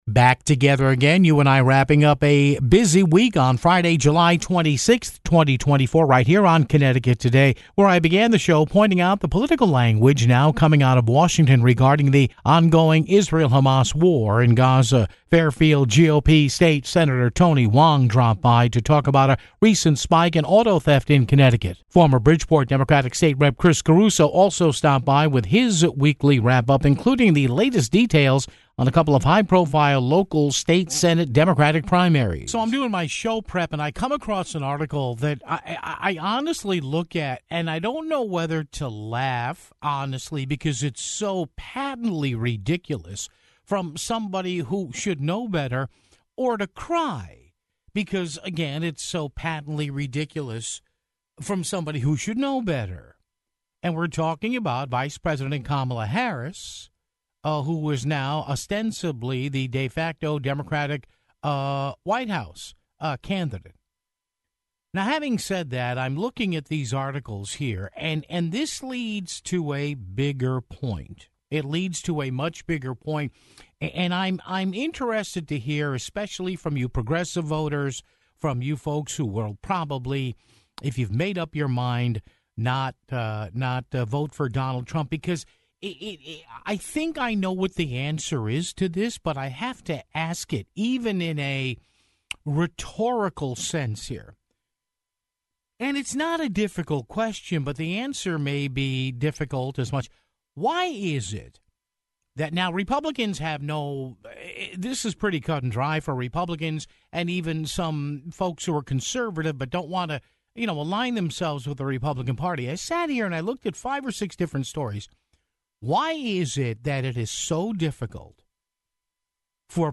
Fairfield GOP State Sen. Tony Hwang talked about a recent spike in auto theft in Connecticut (13:17). Former Bridgeport State Rep. Chris Caruso dropped by with his weekly wrap-up, including the latest details on two high-profile, local State Senate Democratic primaries (21:07)